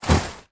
Index of /App/sound/monster/ice_snow_witch
drop_2.wav